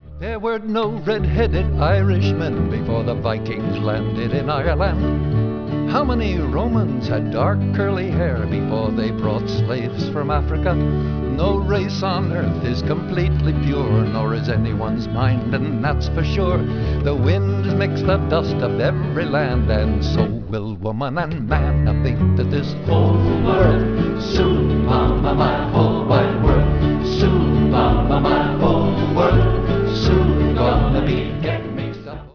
voice, 12-string guitar, whistling
mandolin
bass
surdo, shakers
chorus